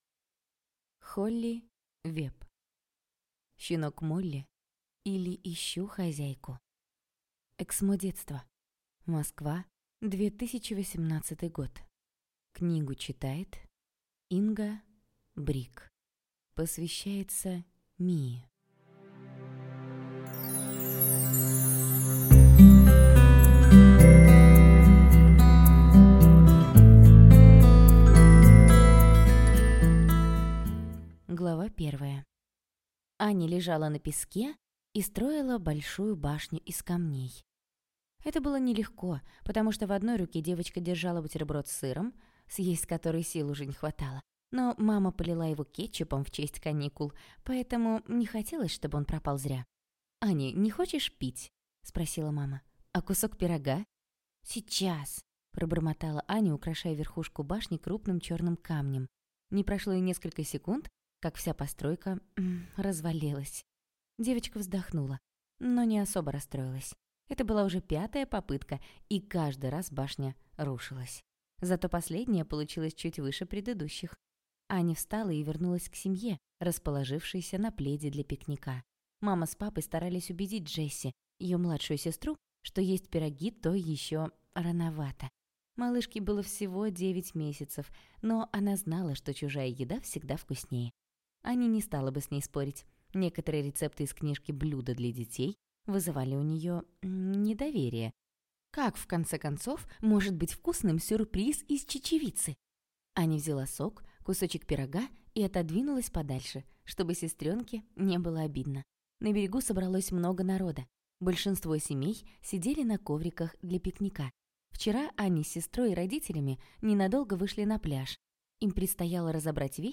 Аудиокнига Щенок Молли, или Ищу хозяйку | Библиотека аудиокниг